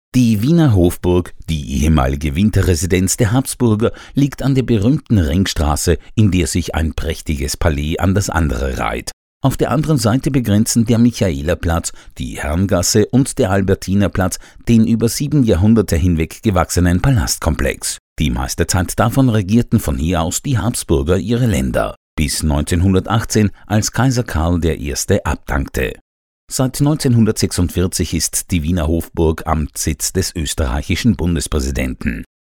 Freundliche tiefe Männerstimme, akzentfrei und hochdeutsch.
Tour Guide
Audioguide Aus Wien